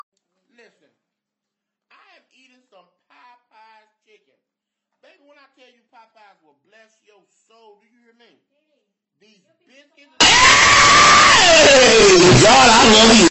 Earrape These Biscuits Earrape